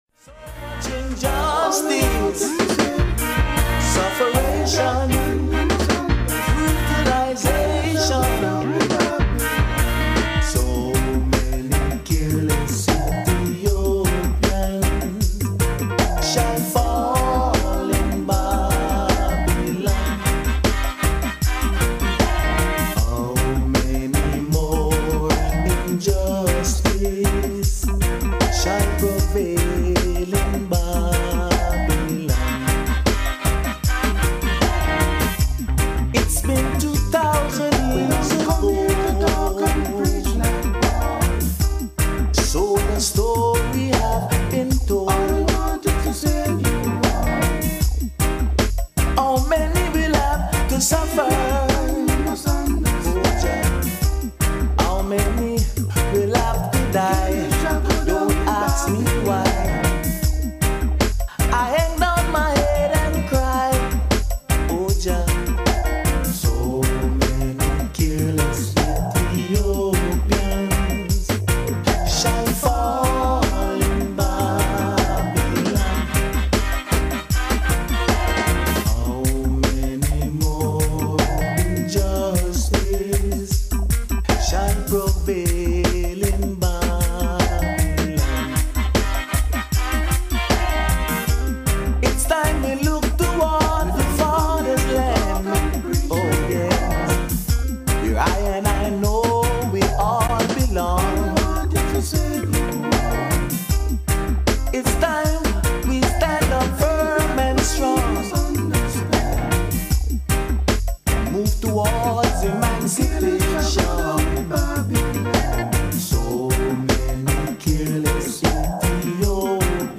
Roots Dub 'n Kultcha !!